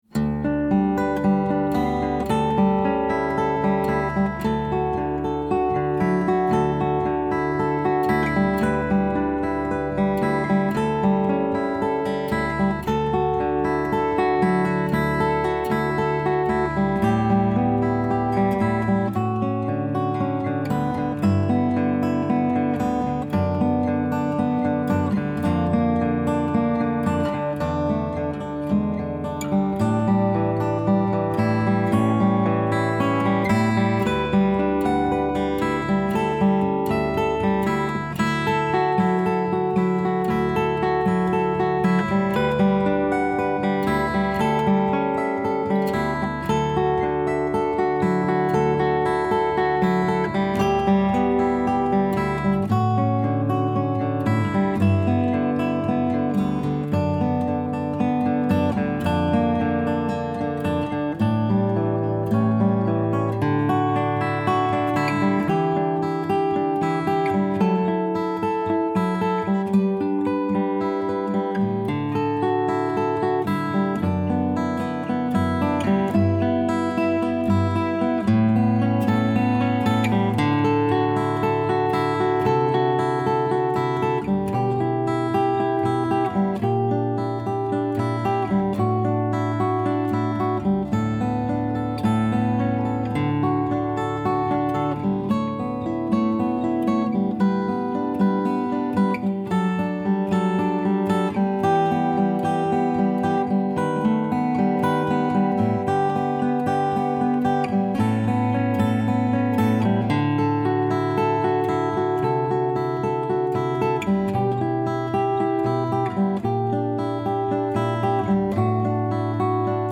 I played my guitar softly and beautiful chords began to appear. I went into my closet to record a new song.
The lyrics will form when they come to me, though my music speaks for me without words.